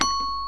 VIRTUAL XYLOPHONE